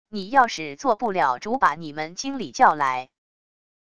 你要是做不了主把你们经理叫来wav音频生成系统WAV Audio Player